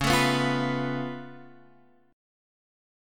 Db+7 chord